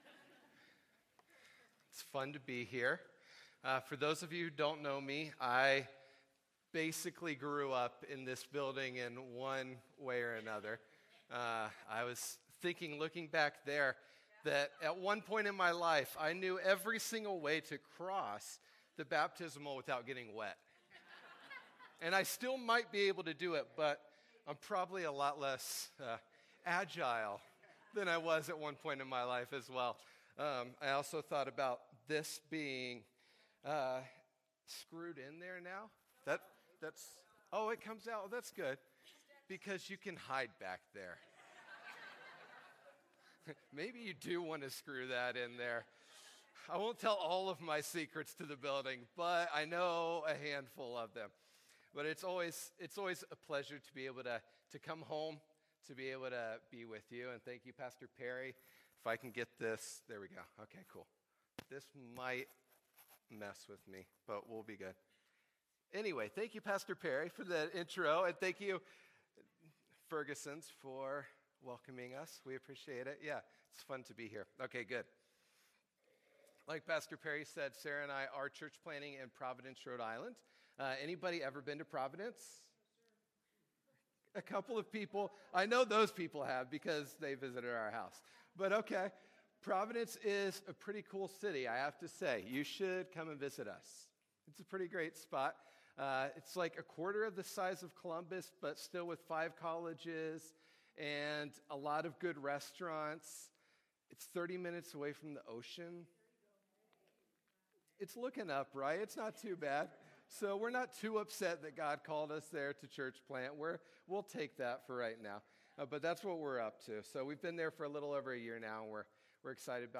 Sermons
Sunday Service